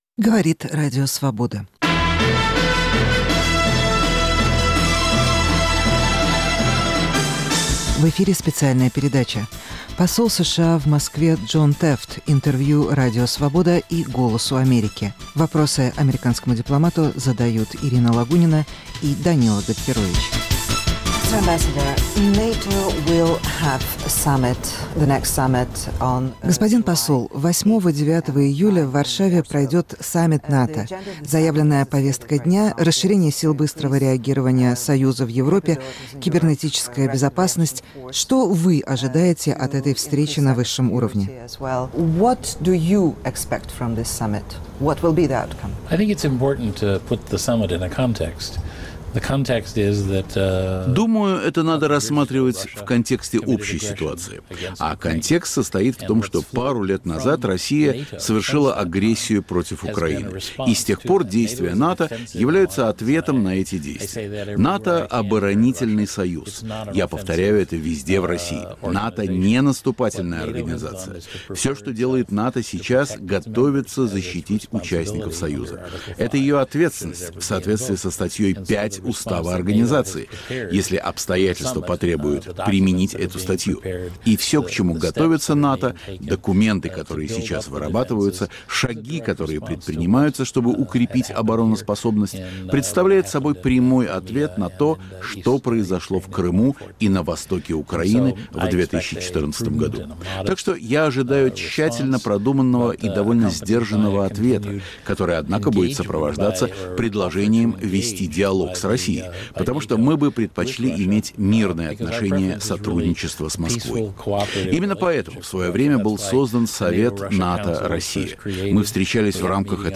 Интервью посла США в Москве Джона Теффта корреспондентам Радио Свобода и «Голоса Америки»